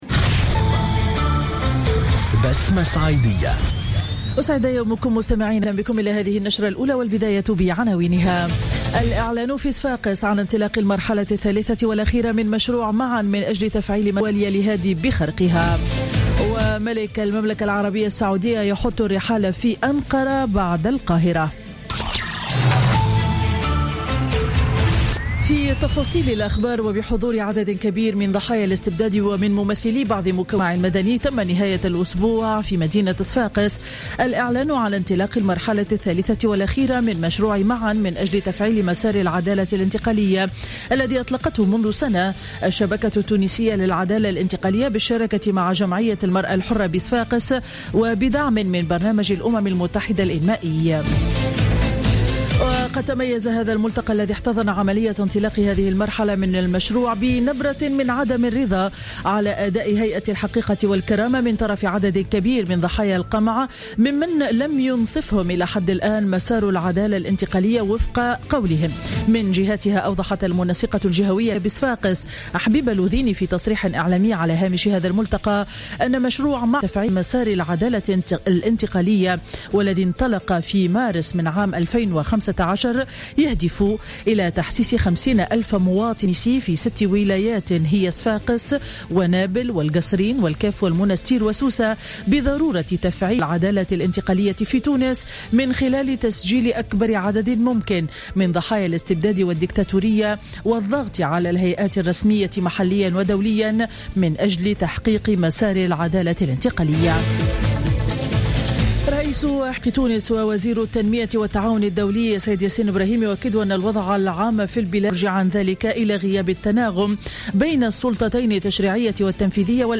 نشرة أخبار السابعة صباحا ليوم الاثنين 11 أفريل 2016